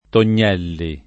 [ ton’n’ $ lli ]